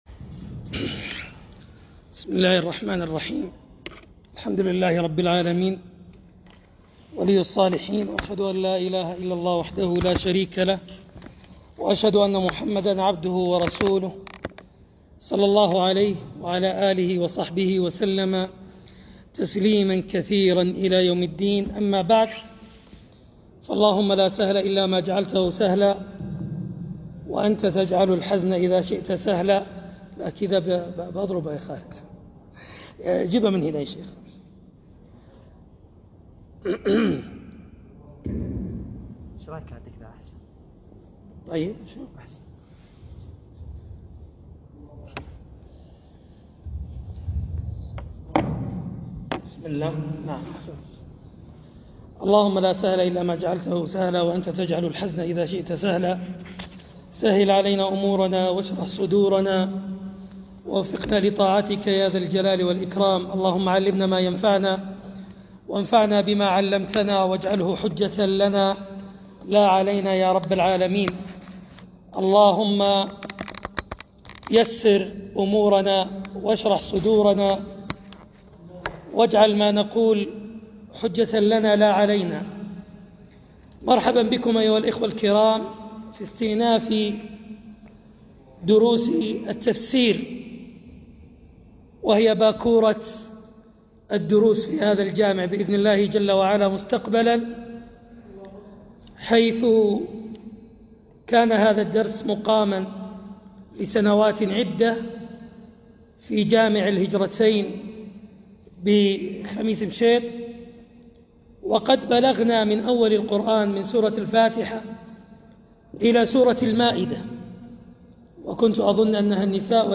درس التفسير سورة المائدة الآية ٦